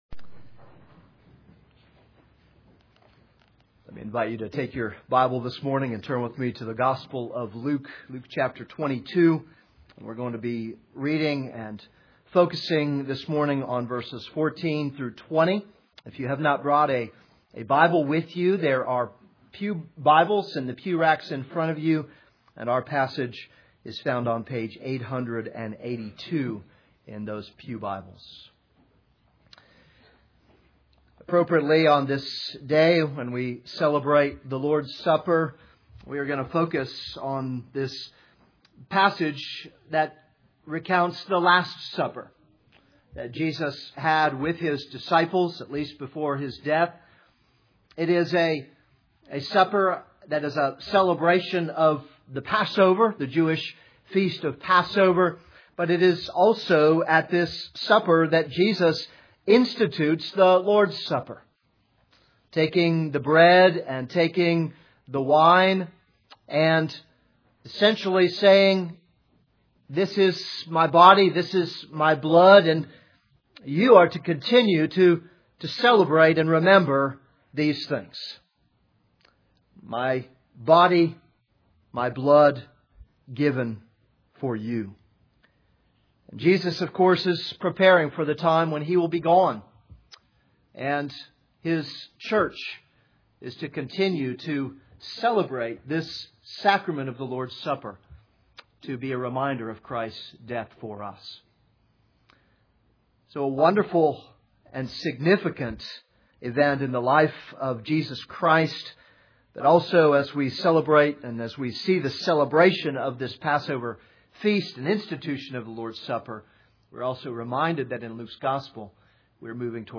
This is a sermon on Luke 22:14-20.